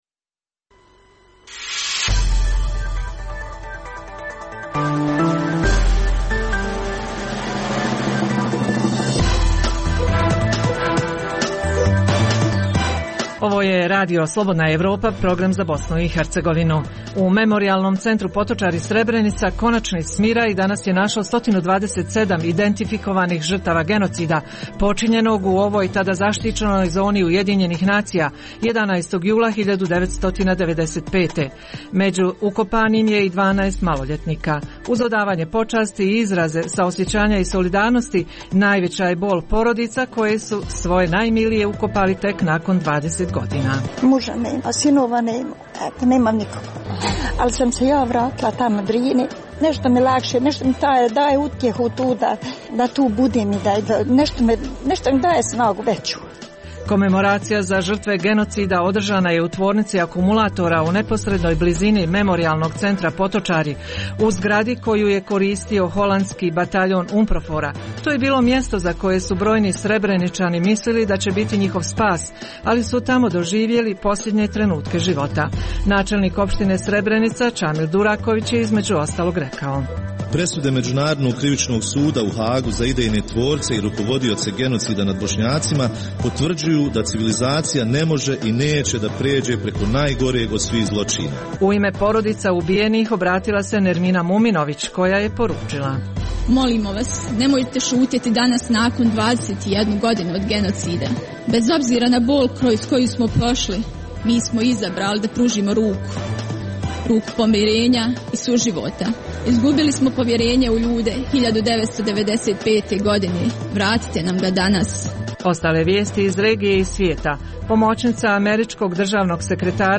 Većim dijelom emisija je posvećena 21. godišnjici genocida u Srebrenici. Ekipa Radija Slobodna Evropa javlja se s lica mjesta, pripremljen je pregled zvaničnih govora na komemoraciji te zabilježene izjave članova porodica ubijenih u ljeto 1995.